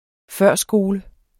Udtale [ ˈfœɐ̯ˀ- ]